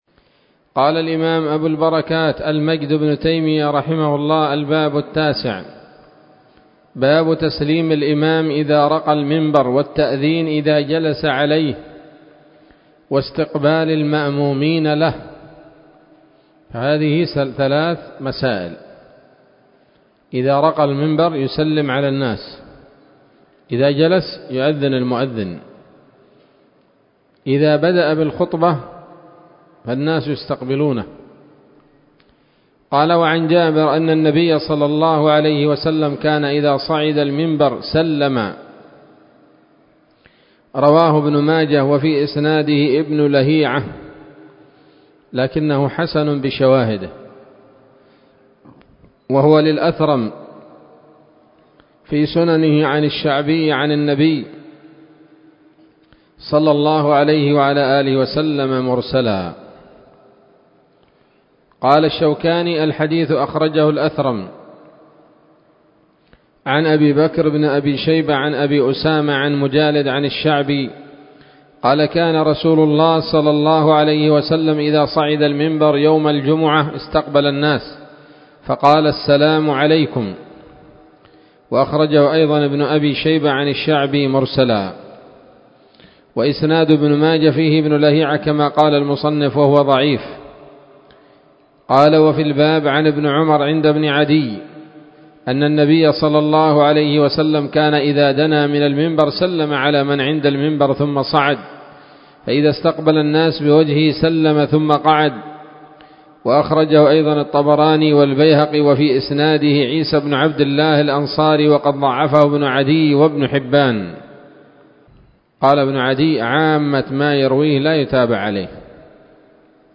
الدرس الرابع والعشرون من ‌‌‌‌أَبْوَاب الجمعة من نيل الأوطار